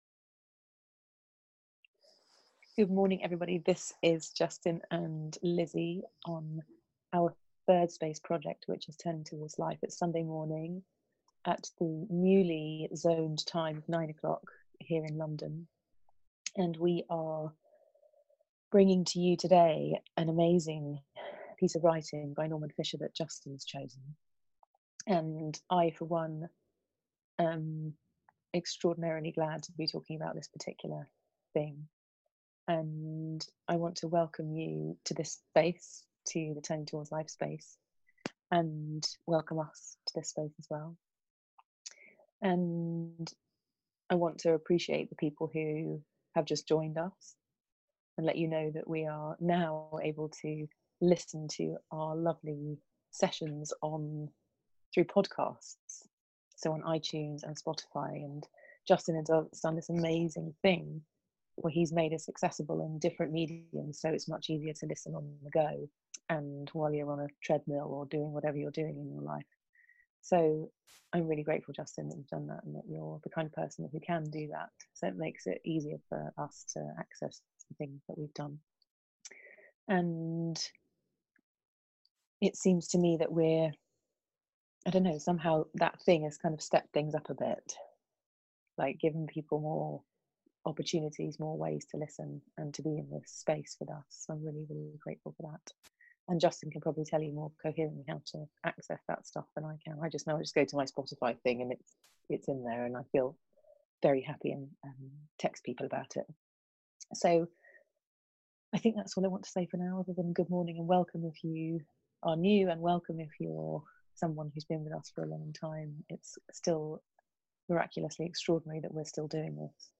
Turning Towards Life is a weekly live 30 minute conversation hosted by Thirdspace